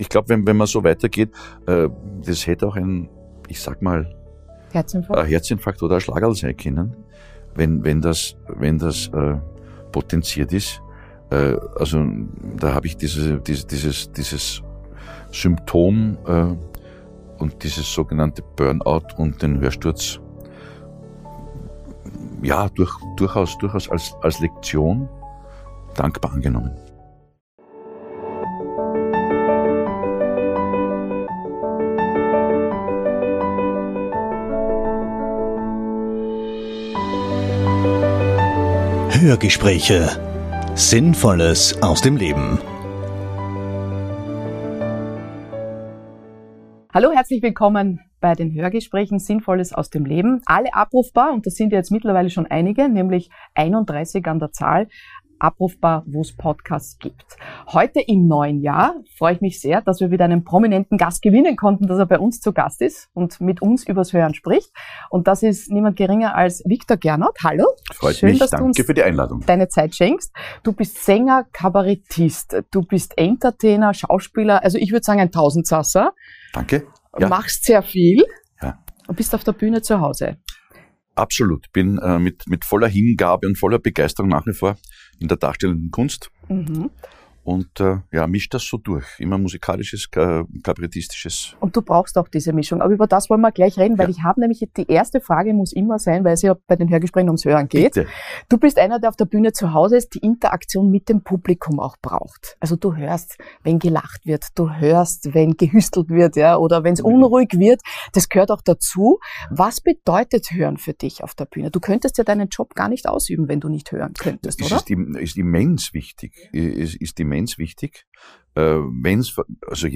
Heute, über zehn Jahre später, sieht Viktor Gernot diese Stressreaktionen seines Körpers und seiner Seele als wichtiges Korrektiv, für das er sehr dankbar ist. So erzählt er uns über seine zahlreichen aktuellen Projekte, aber auch, wo er seine ausgleichende Ruhe findet. Ein sehr schönes Hörgespräch über die Kunst der Unterhaltung, der Krisenbewältigung und wir man beides unter einen Hut bringt.